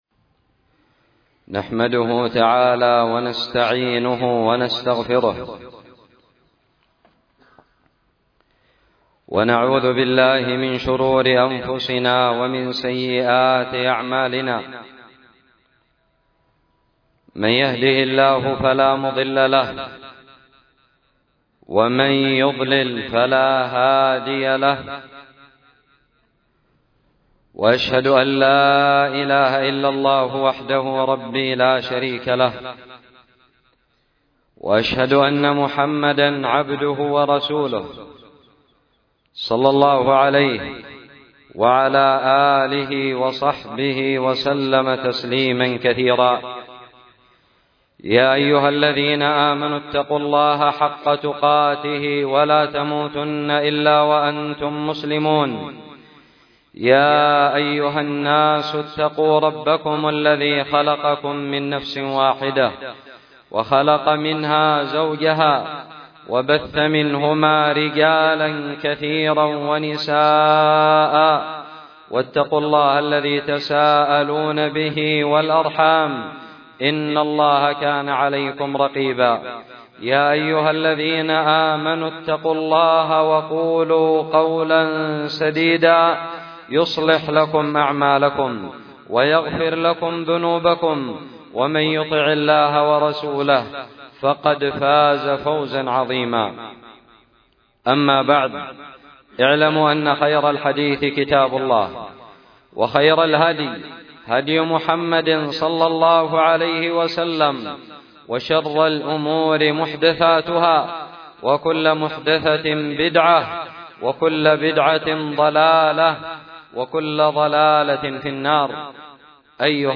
خطب الجمعة
ألقيت بدار الحديث السلفية للعلوم الشرعية بالضالع في عام 1438هــ